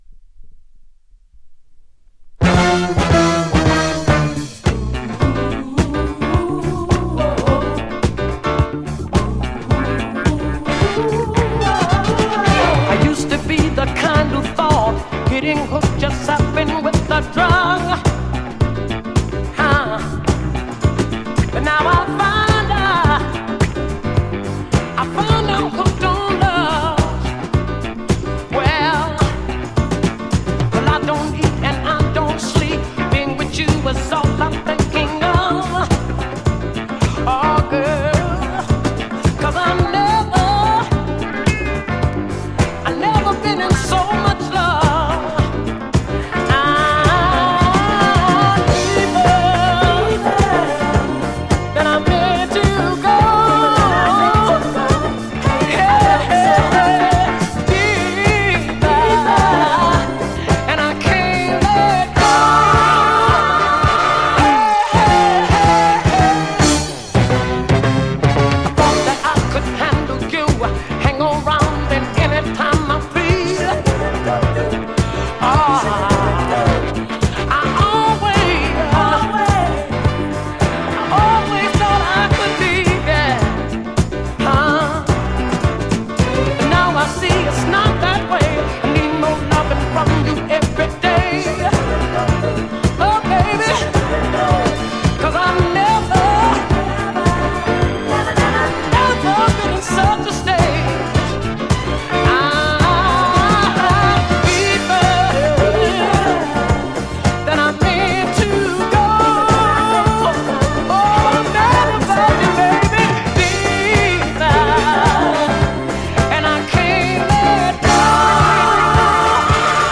ジャンル(スタイル) DISCO / SOUL / FUNK